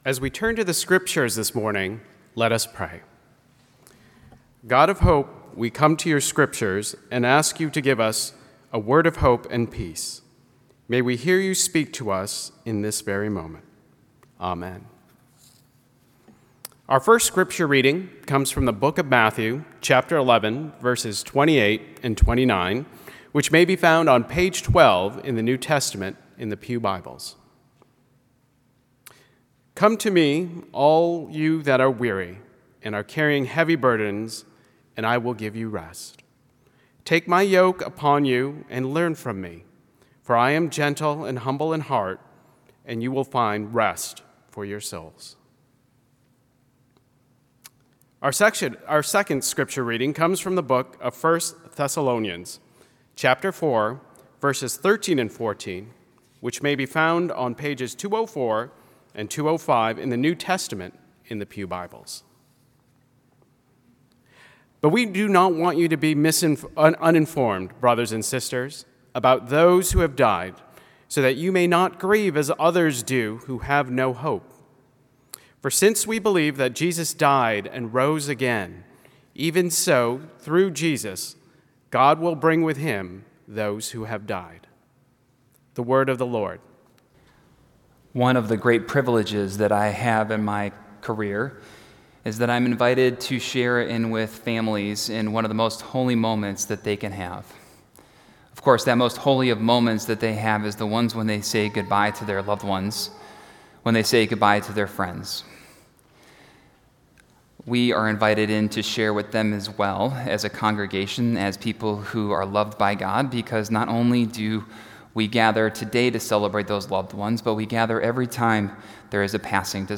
November 4, 2018 – Service of Remembrance – Park Ridge Presbyterian Church
A message